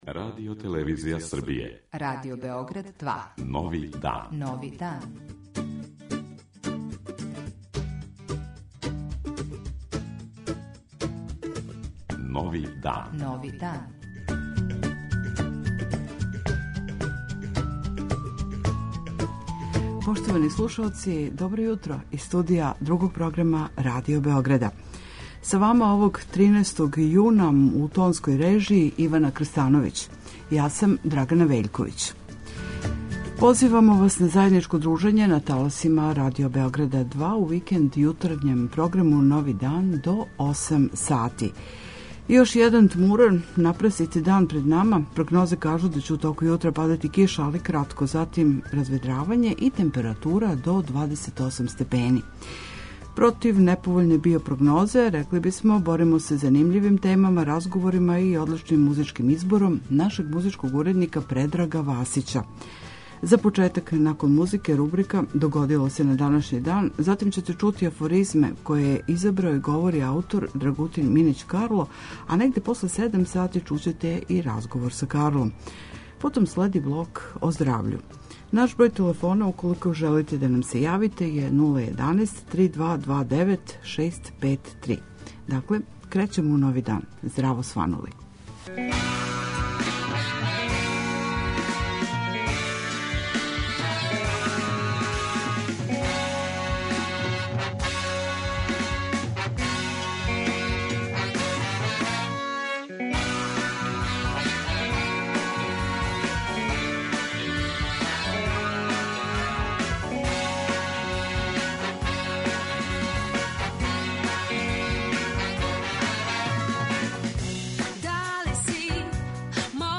Јутарњи викенд програм магазинског типа